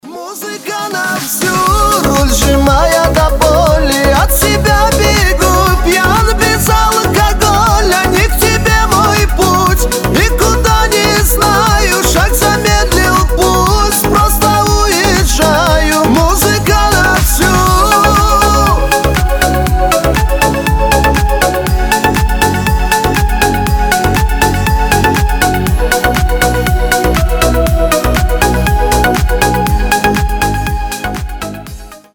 Громкие рингтоны
Шансон рингтоны